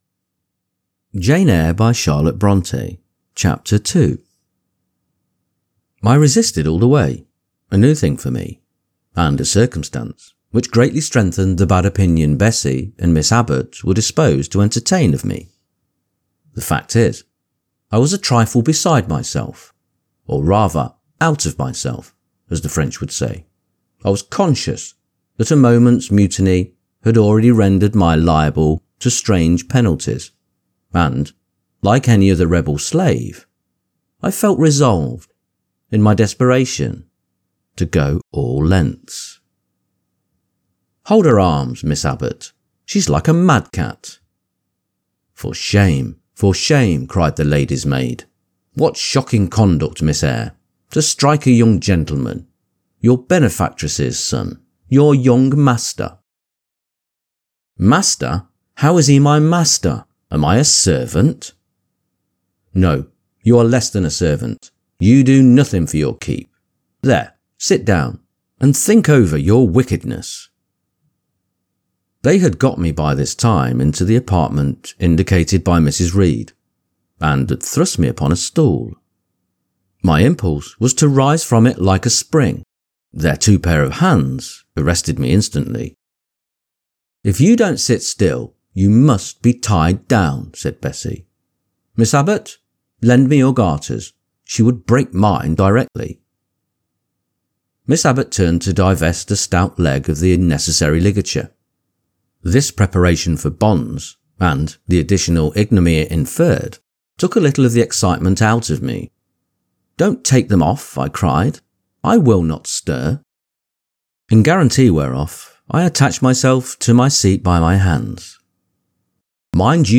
Jane Eyre – Charlotte Bronte – Chapter 2 | Narrated in English - Dynamic Daydreaming